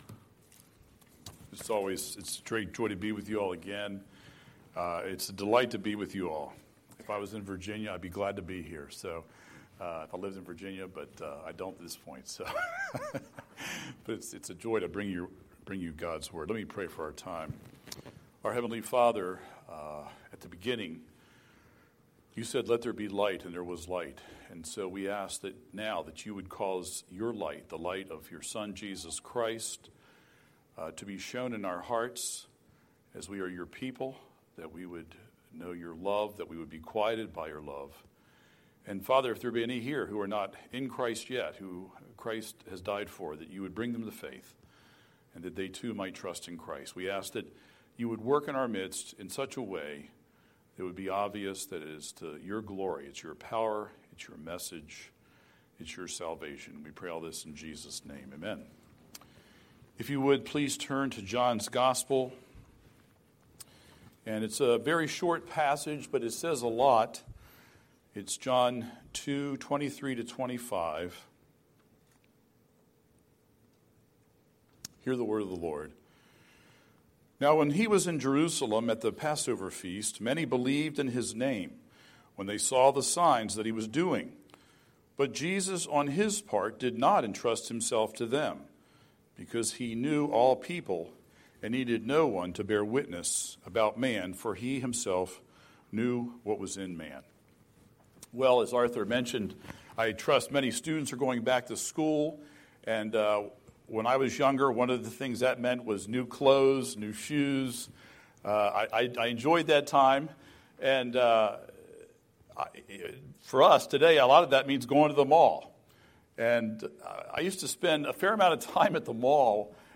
Scripture: John 2:23-25 Series: Sunday Sermon